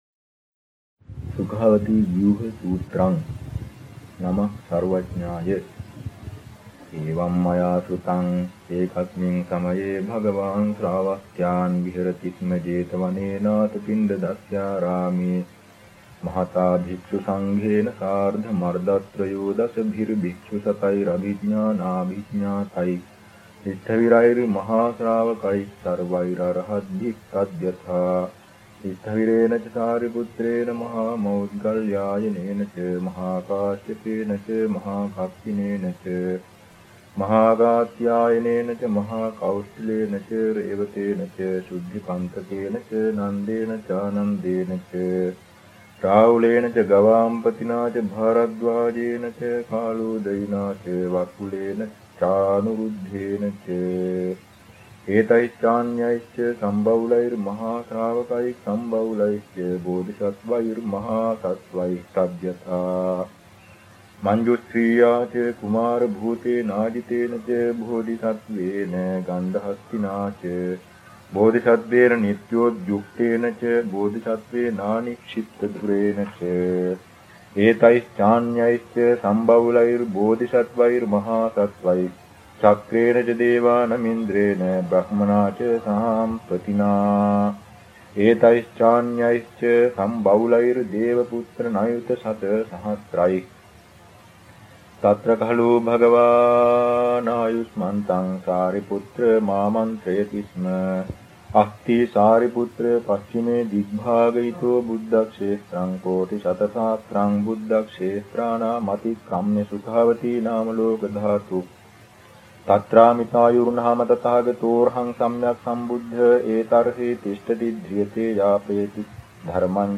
Sanskrit chant of the Amitabha Sutra
at the Sudhamma Wansarama Maha Viharaya in Kirimetiyana, Sri Lanka
Chanting.MP3